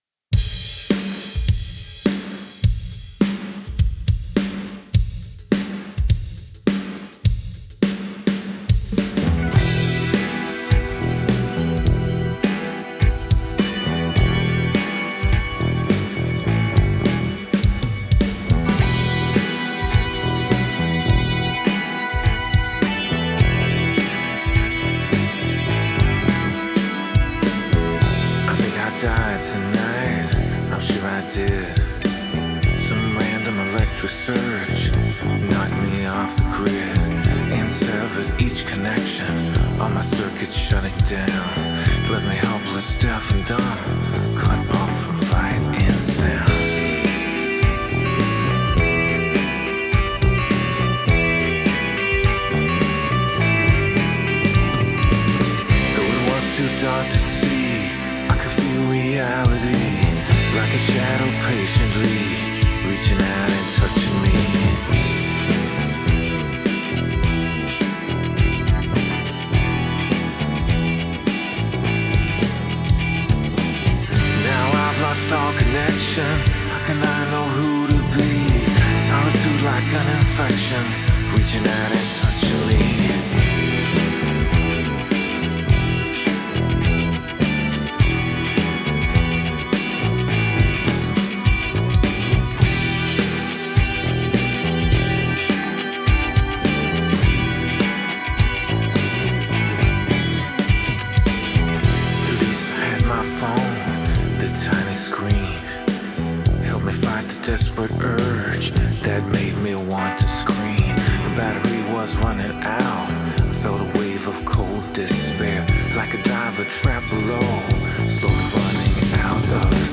Bass, Synths, Rhodes, Backing Vocals
Lead Vocal, Backing Vocals
Guitars
Keys, SFX
Guitars, incl. Guitar Solo
Drums
Backing Vocals